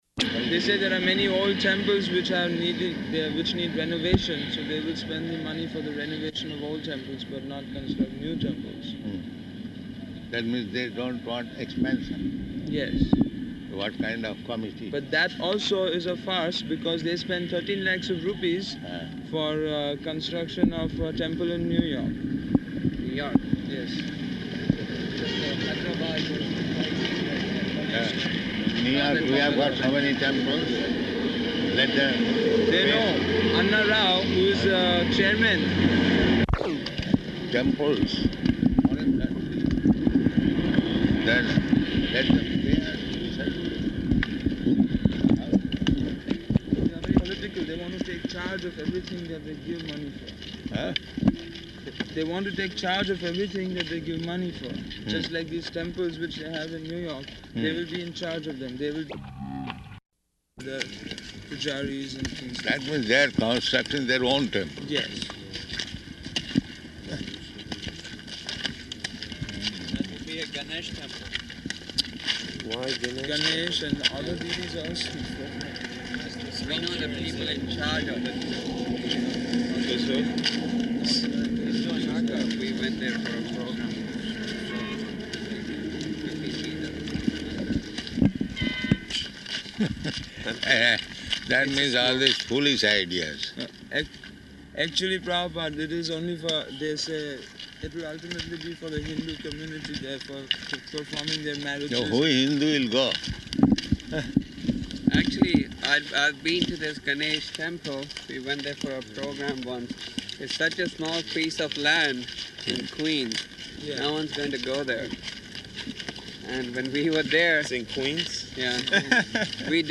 Type: Walk
Location: Nellore